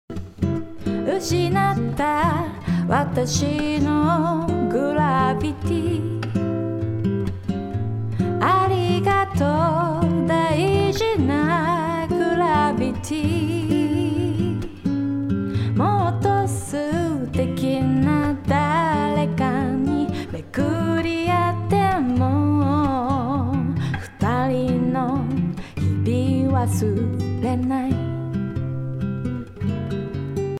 置き換えたところで、、、先ほどダイナミックEQがかかっていた帯域を選択してコンプをかけてみます。
Pro-MBの場合、音が圧縮された感じが残るので「ある帯域を抑えたい」という元々の目的とは結果が少しずれてしまったようです。